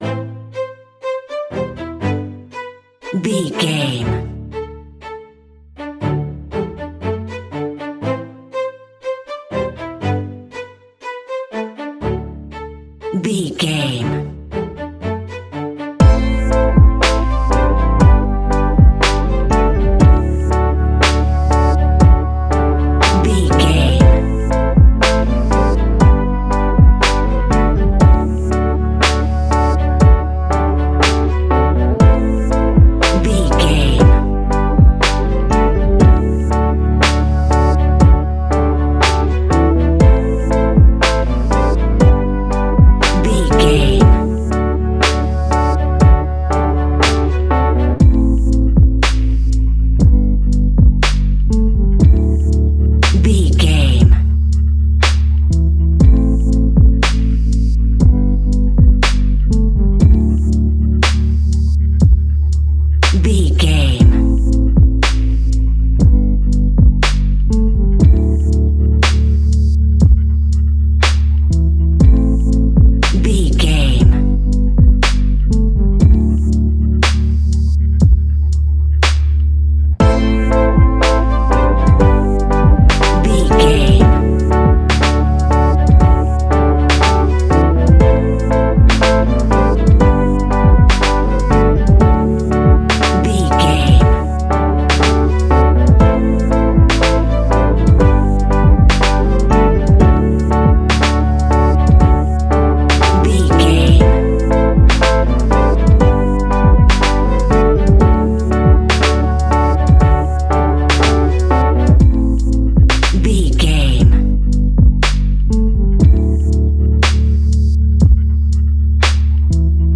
Ionian/Major
D♭
laid back
sparse
new age
chilled electronica
ambient